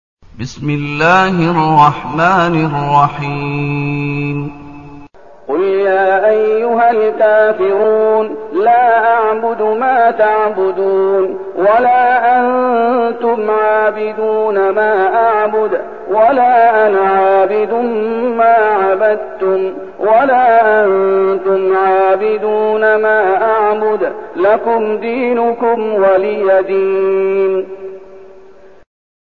المكان: المسجد النبوي الشيخ: فضيلة الشيخ محمد أيوب فضيلة الشيخ محمد أيوب الكافرون